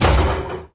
sounds / mob / irongolem / hit2.ogg
hit2.ogg